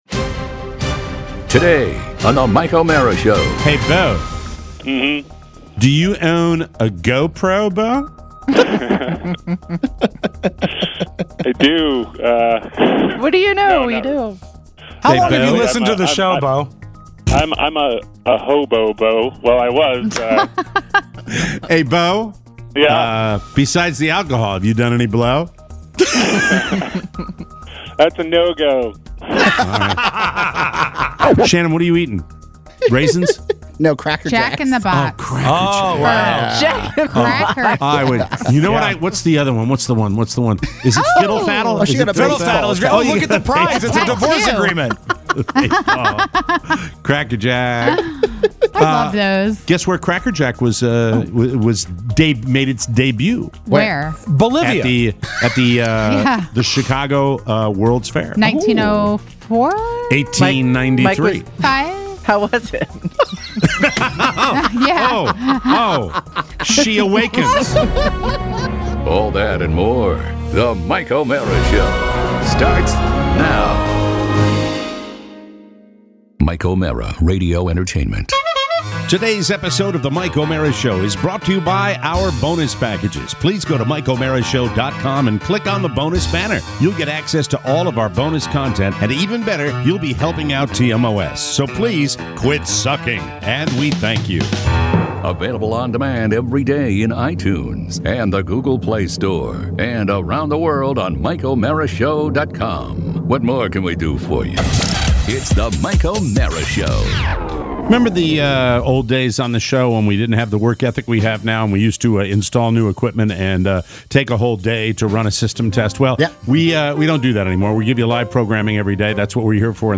It's the tenth TMOS cocktail party! Despite a few technical issues we still have plenty of your calls and even more drinks.